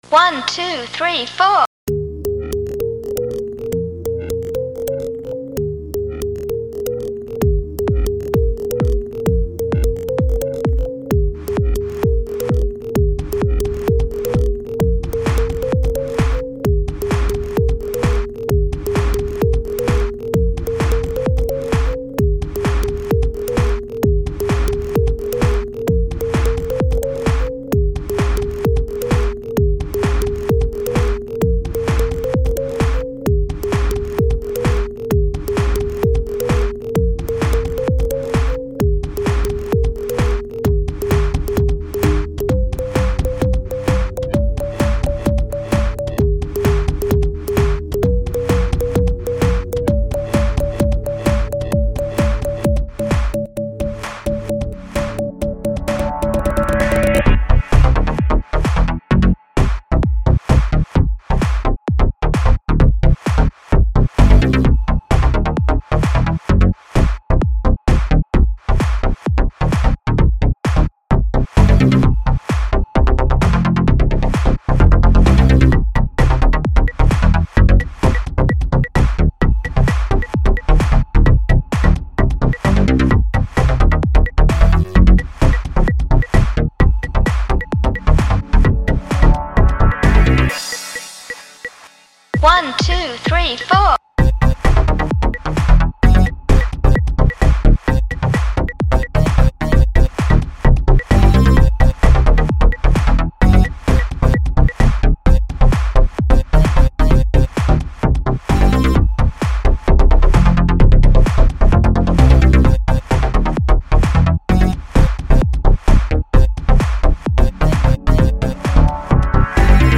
Прикольный клубнячёк!!! ставлю 5